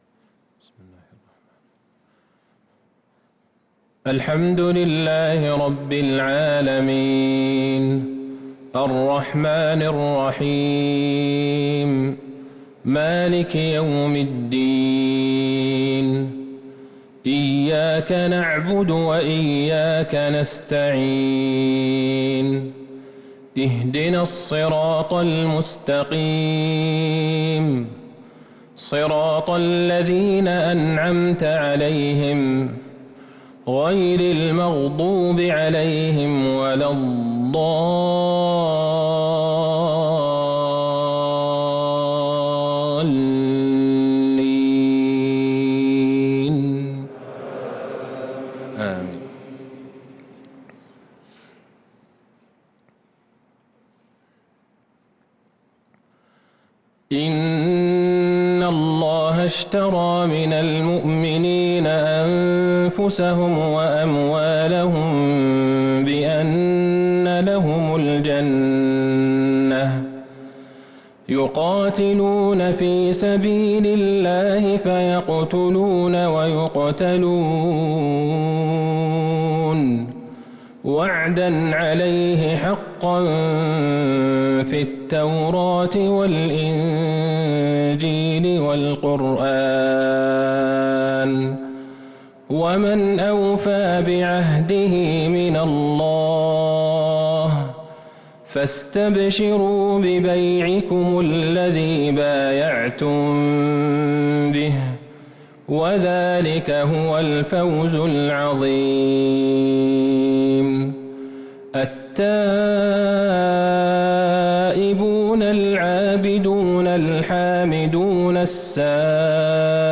صلاة الفجر للقارئ أحمد بن طالب حميد 23 رمضان 1443 هـ
تِلَاوَات الْحَرَمَيْن .